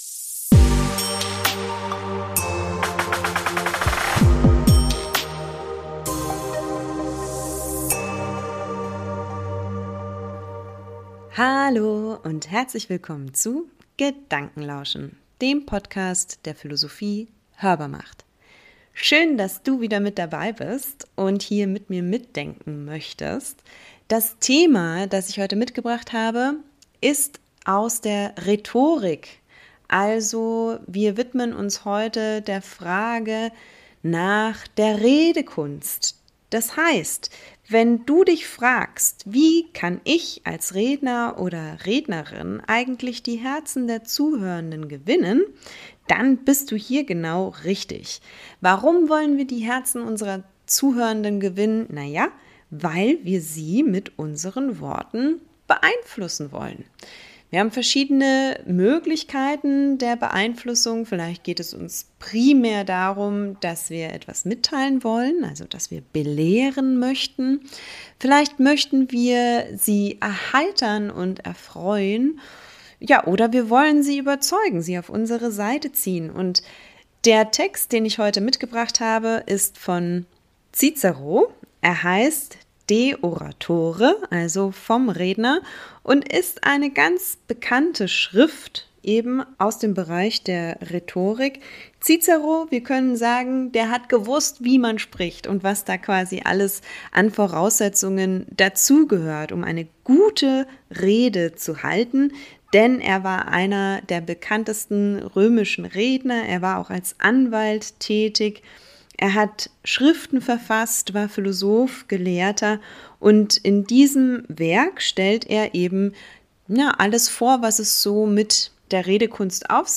Hier lese ich eine Stelle aus Ciceros De Oratore vor, in der er die römischen Gerichtsredner Crassus und Marcus Antonius darü...